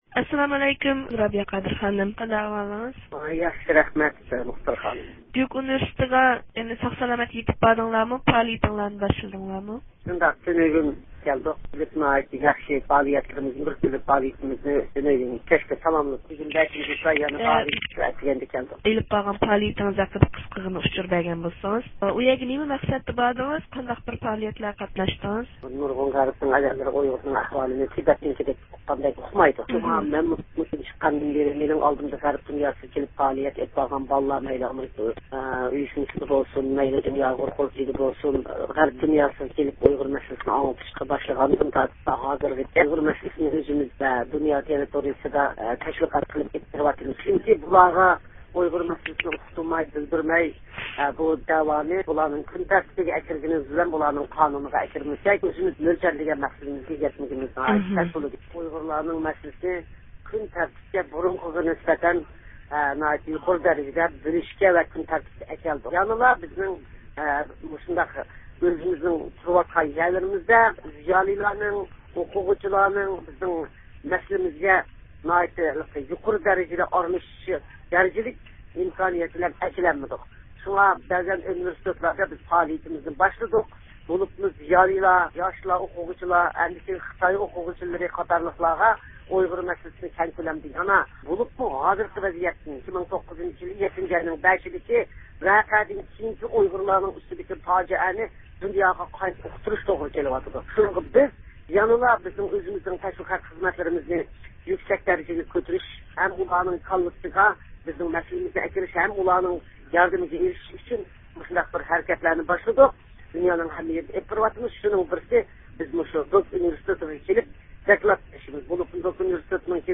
ئاۋاز ئۇلىنىشىدىن رابىيە قادىر خانىم بىلەن مۇخبىرىمىزنىڭ ئۆتكۈزگەن سۆھبىتىگە ئىشتىراك قىلغايسىلەر.